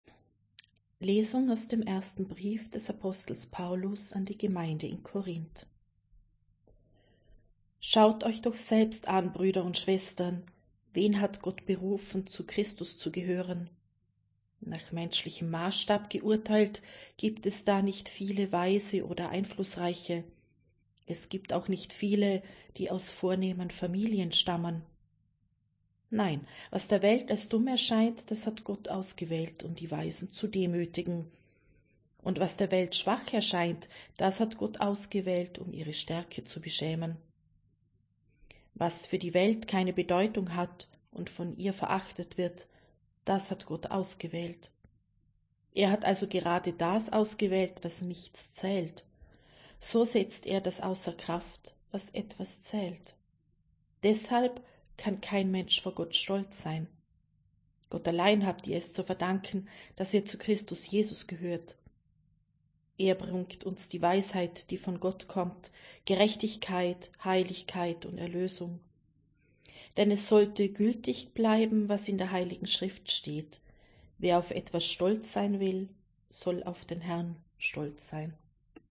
Wenn Sie den Text der 2. Lesung aus dem ersten Brief des Apostels Paulus an die Gemeinde in Korínth anhören möchten:
Wir wollen einen Versuch starten und werden ab dem Beginn des neuen Lesejahres die Texte in der Länge der biblischen Verfasser lesen.
2.-Lesung-4.2.26.mp3